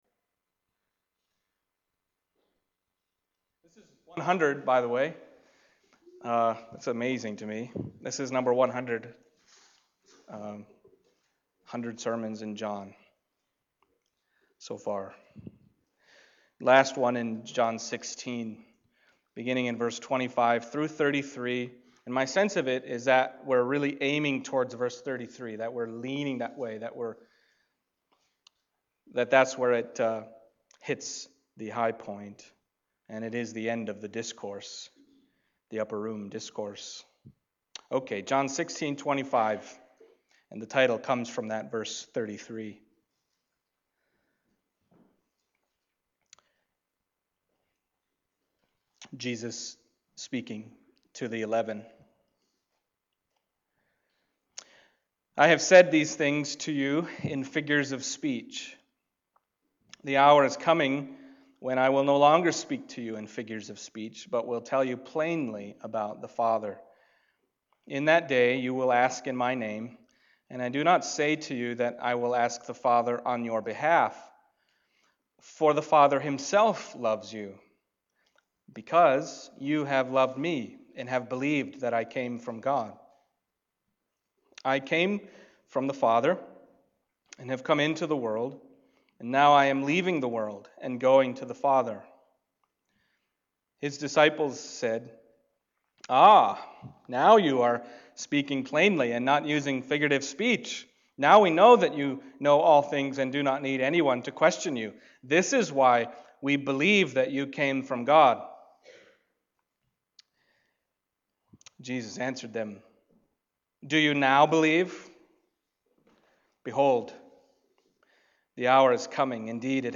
John Passage: John 16:25-33 Service Type: Sunday Morning John 16:25-33 « The Purpose and Ground and Result of Real Prayer And Then God Prayed to God…